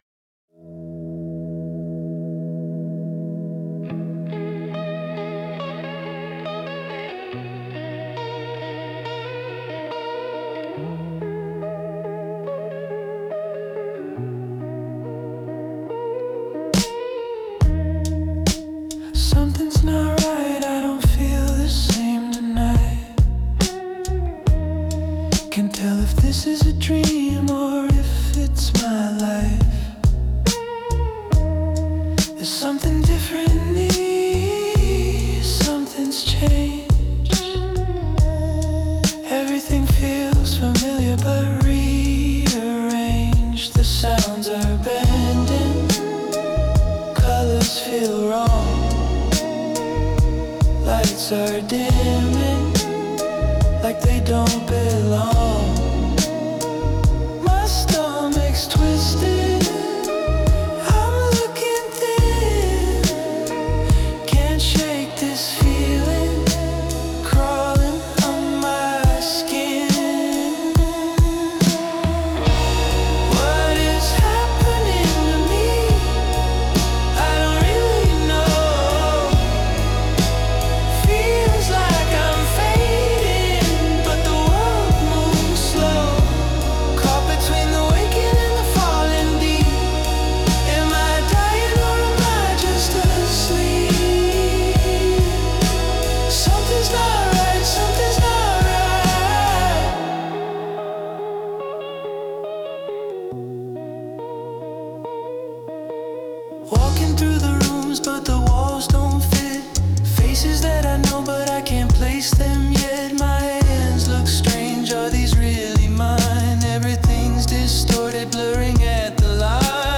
Indie Rock • Conflict • Internal Struggle • Drama